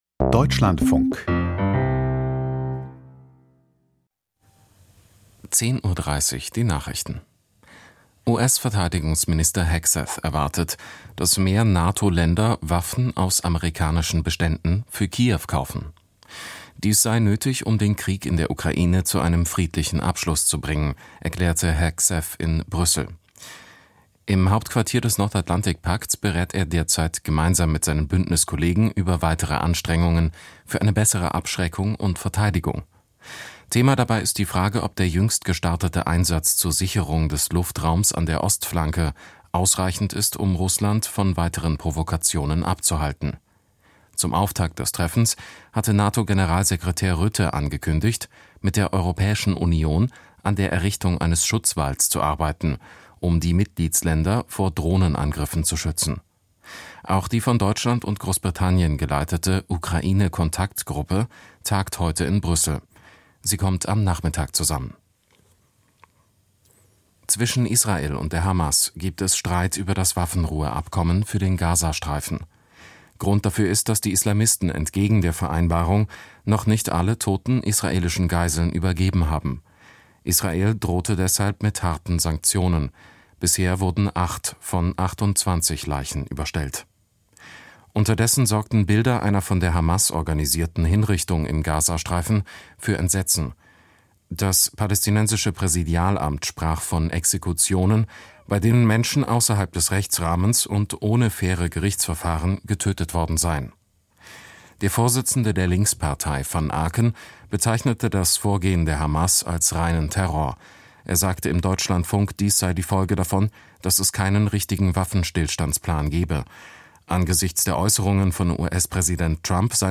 Die Nachrichten vom 15.10.2025, 10:29 Uhr